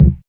Percs
PERC.113.NEPT.wav